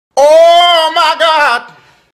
Ohhh-My-God-Sound-effect.mp3
MKyqDTxEIBr_Ohhh-My-God-Sound-effect.mp3